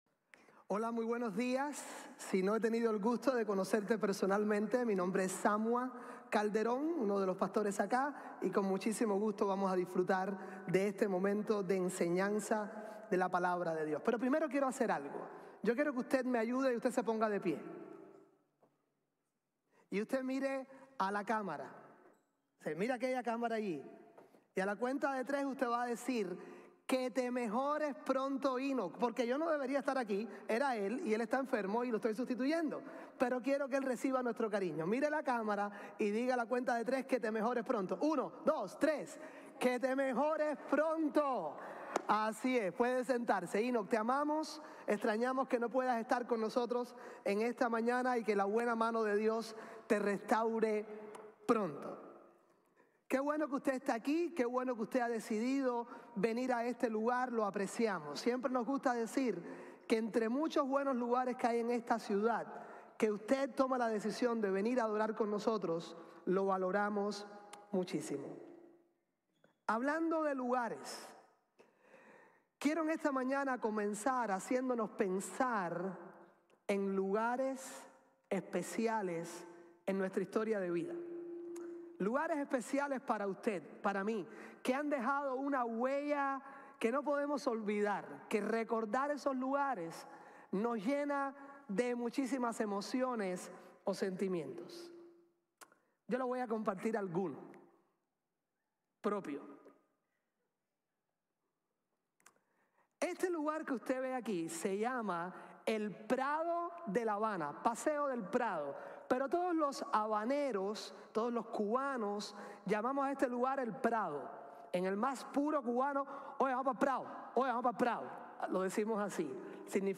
lugares especiales, lecciones importantes | Sermon | Grace Bible Church